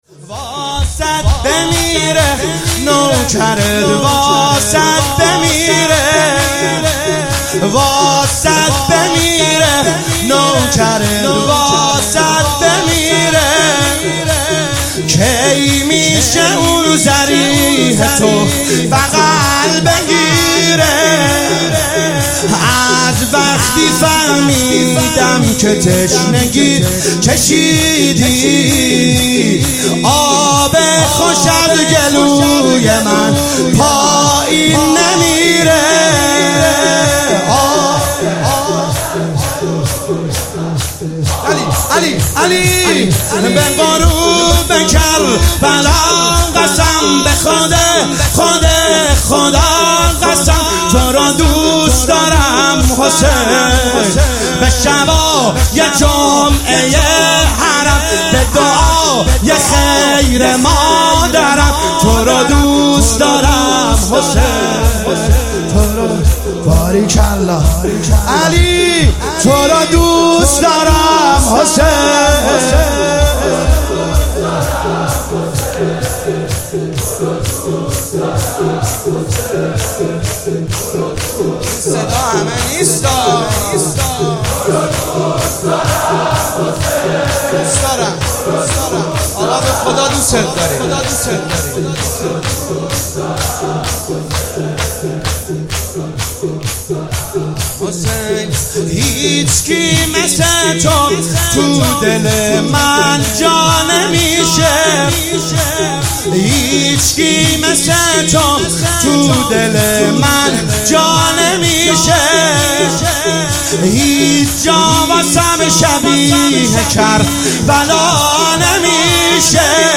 شب نوزدهم رمضان1401 -شور - واست بمیره